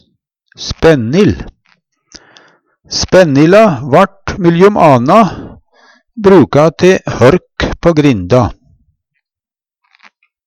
spennil - Numedalsmål (en-US)